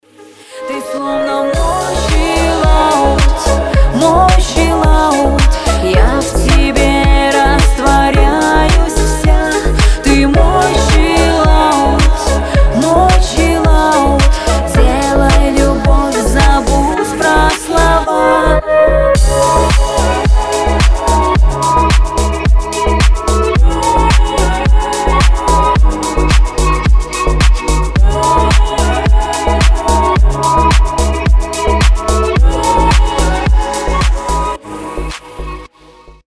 • Качество: 320, Stereo
deep house
чувственные